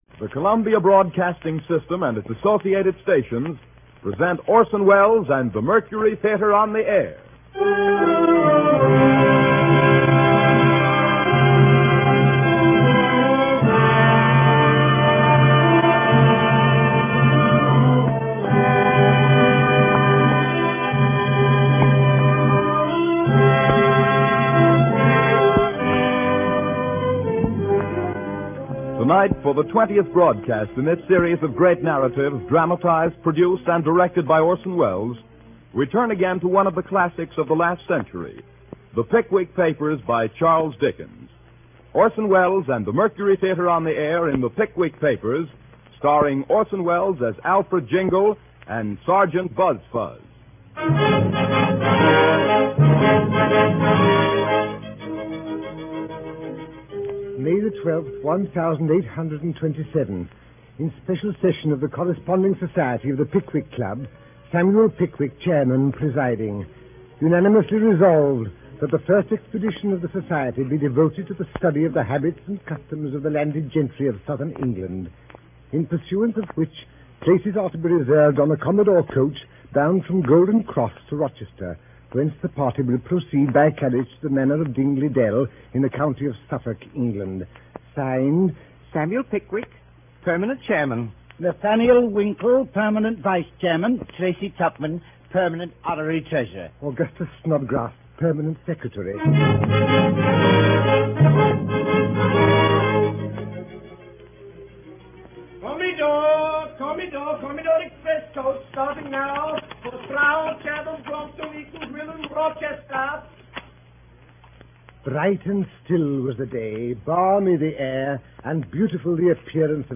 Orson Welles On The Radio - A collection of radio shows that Orson Wells appeared in or produced.
… continue reading 18 episodes # Audio Drama # [email protected] (Entertainment Radio # Entertainment Radio